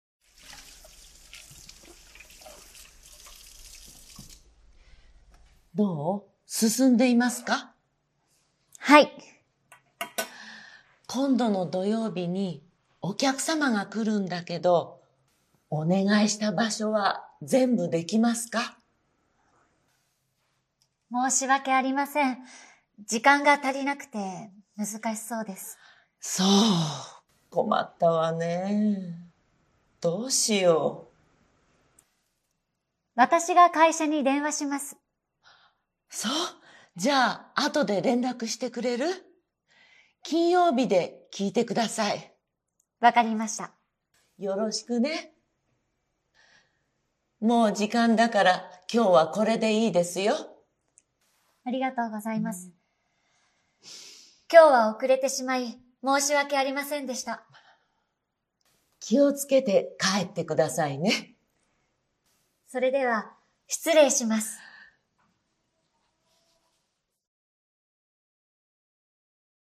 Role-play Setup
skit07.mp3